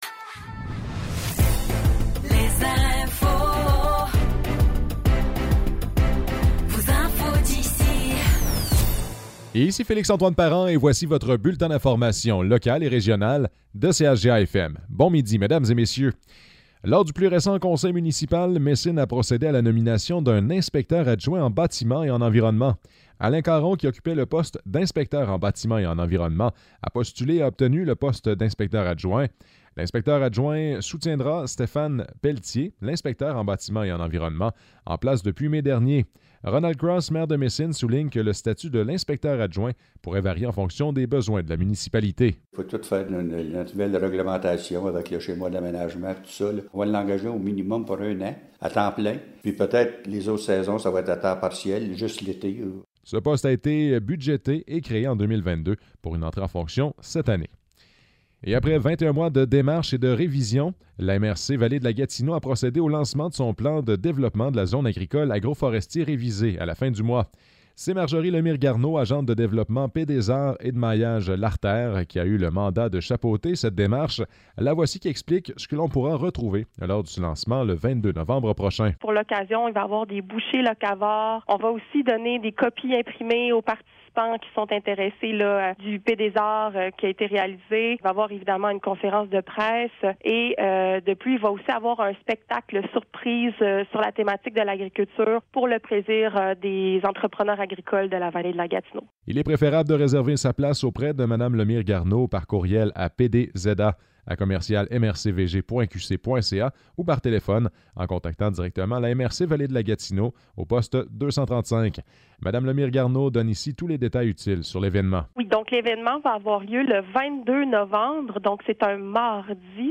Nouvelles locales - 1er novembre 2023 - 12 h